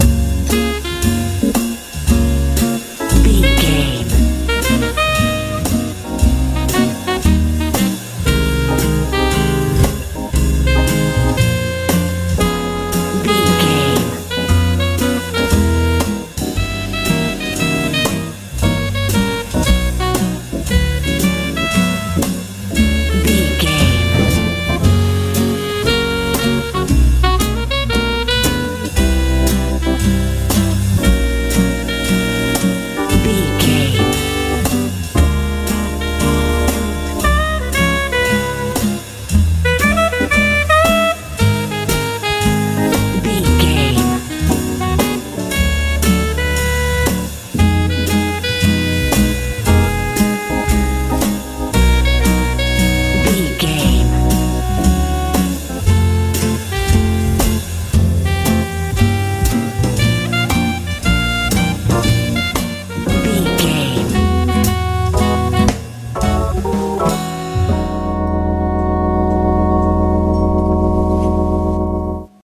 jazz
Ionian/Major
C♯
groovy
funky
acoustic guitar
bass guitar
drums
saxophone
organ
sensual
romantic
soft
soothing
relaxed